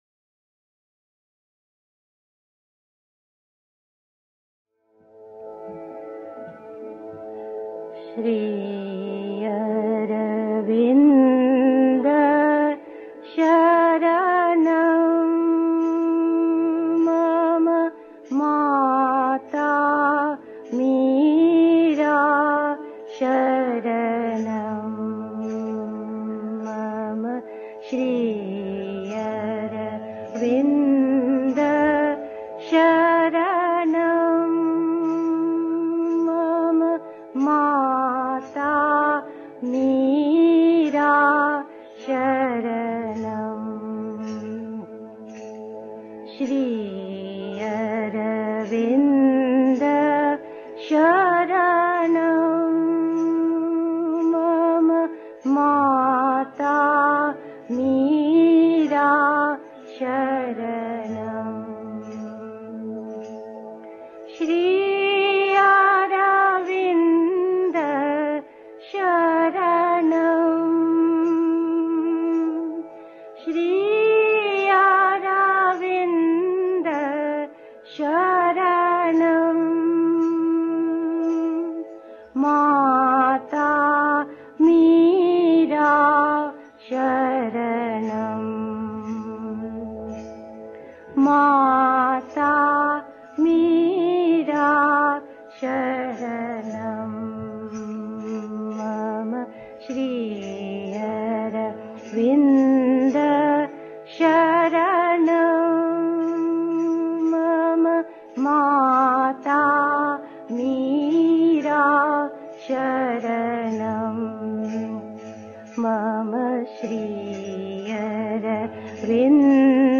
1. Einstimmung mit Musik. 2. Der supramentale Yoga (Sri Aurobindo, CWSA, Vol. 12, pp. 169-71) 3. Zwölf Minuten Stille.